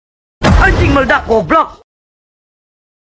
Efek Suara Anjing Meledak Goblok
Kategori: Suara viral
efek-suara-anjing-meledak-goblok-id-www_tiengdong_com.mp3